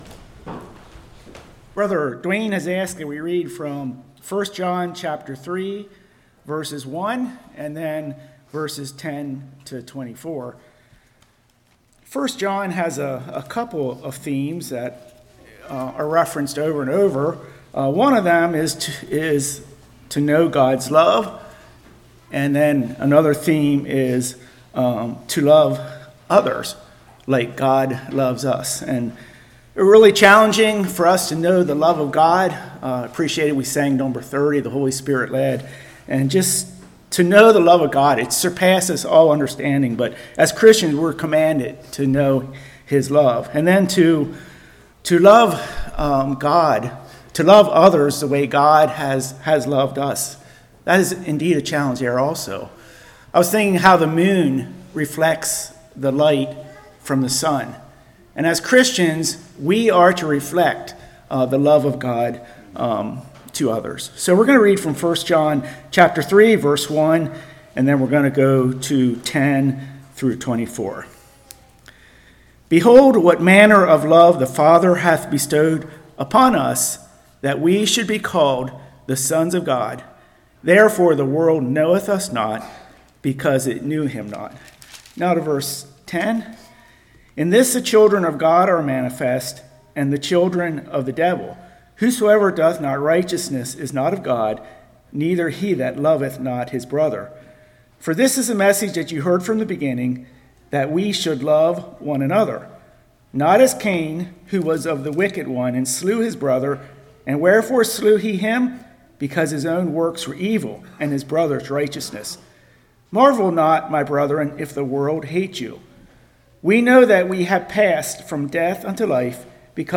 Passage: 1 John 3:1, 10-24 Service Type: Morning